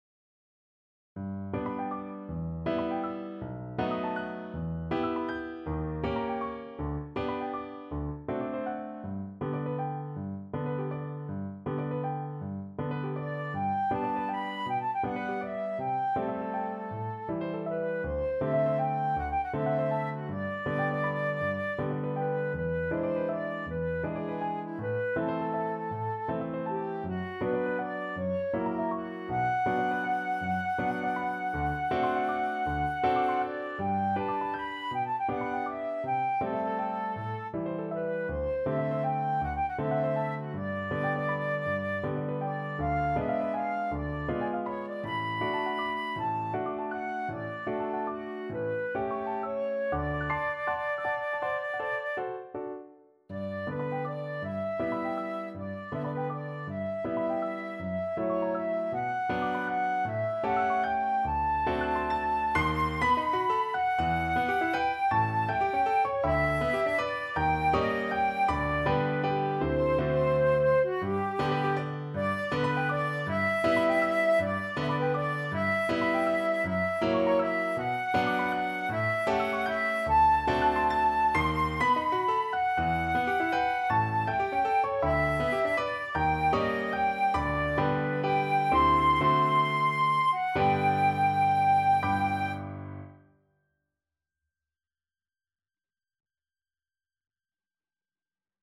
Flute
G major (Sounding Pitch) (View more G major Music for Flute )
6/8 (View more 6/8 Music)
Classical (View more Classical Flute Music)